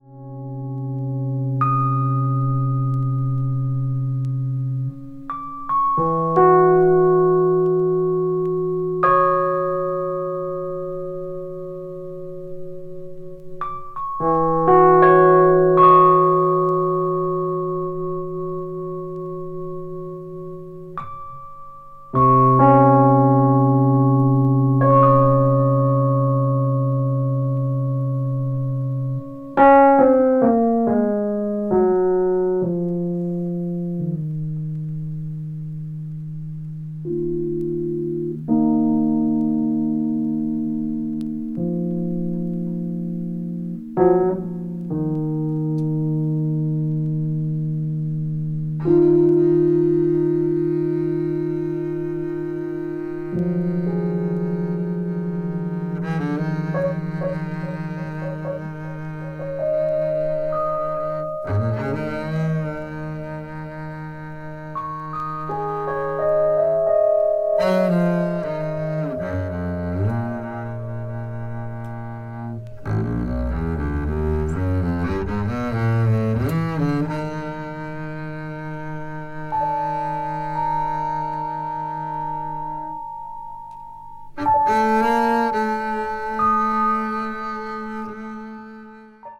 duo album